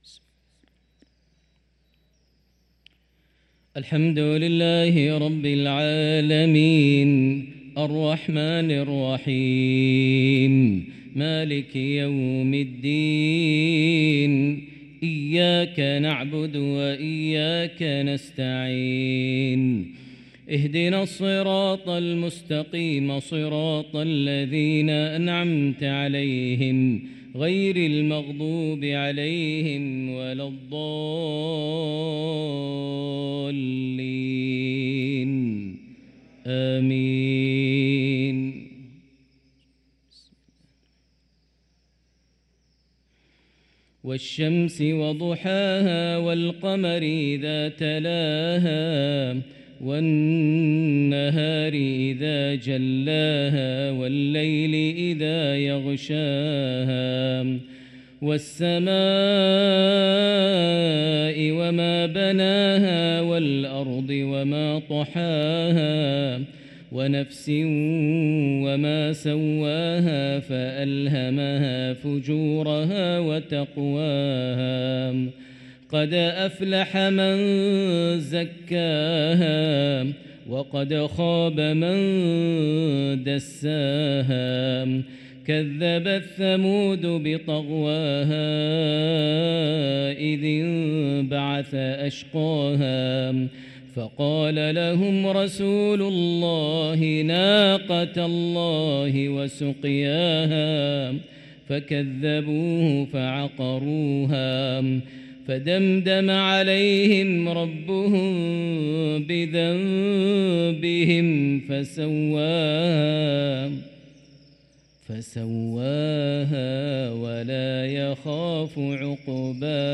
صلاة المغرب للقارئ ماهر المعيقلي 15 جمادي الأول 1445 هـ
تِلَاوَات الْحَرَمَيْن .